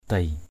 /d̪eɪ/ (đg.) sàng sảy = trier (le riz) sur un tamis par petites secousses. adei kamei dei brah ad] km] d] bH em gái sàng gạo.